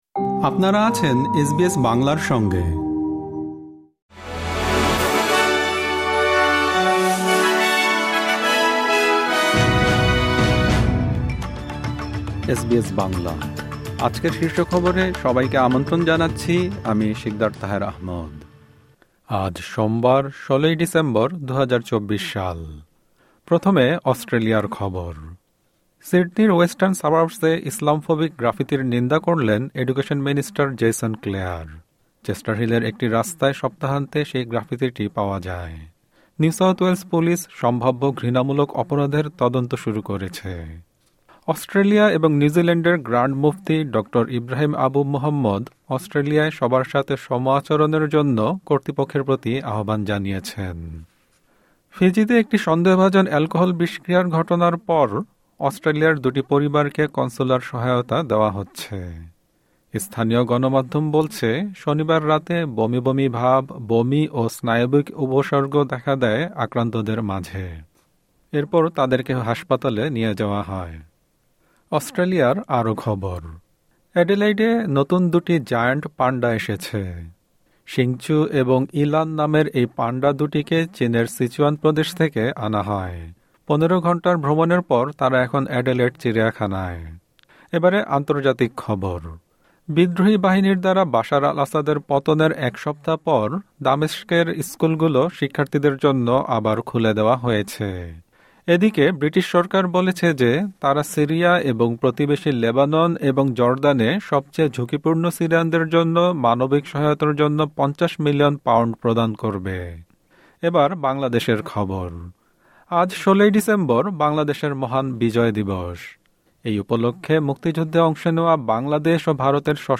এসবিএস বাংলা শীর্ষ খবর: ১৬ ডিসেম্বর, ২০২৪।